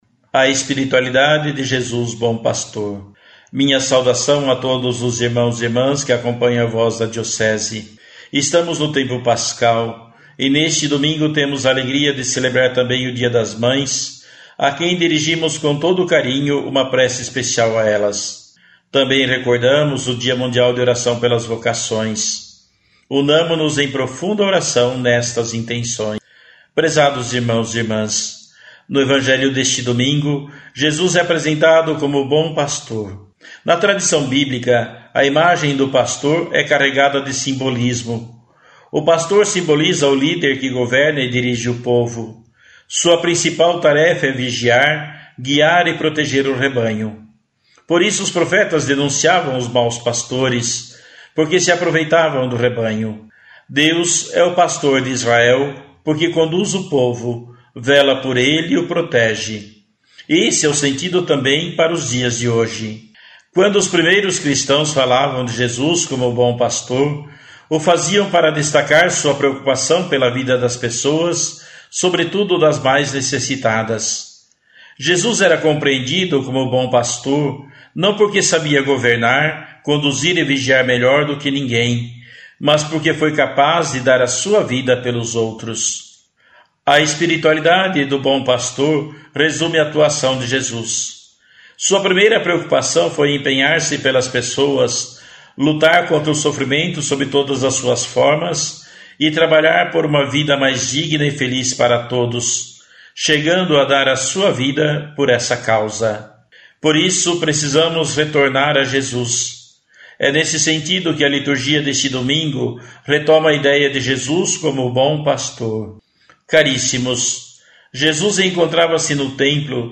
Dom Adimir Antonio Mazali – Bispo Diocesano de Erexim – RS